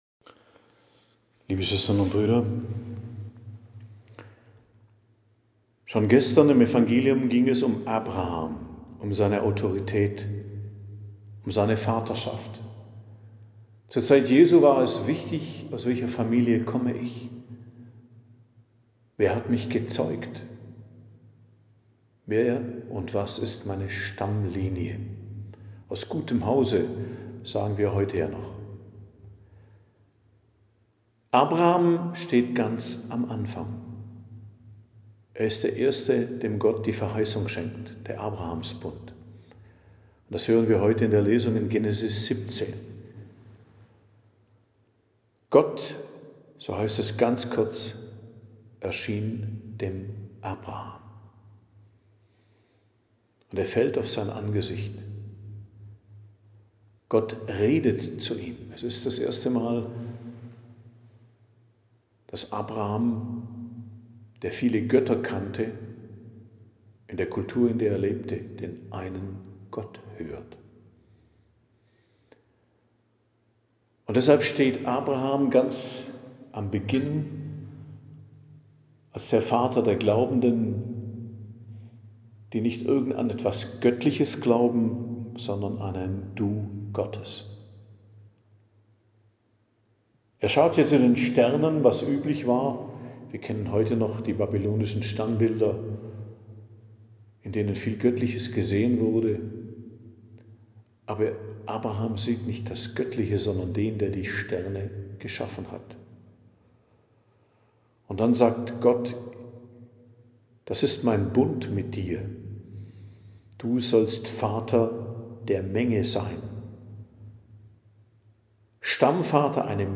Predigt am Donnerstag der 5. Woche der Fastenzeit, 10.04.2025